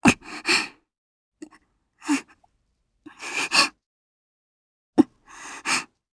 Gremory-Vox_Sad_jp.wav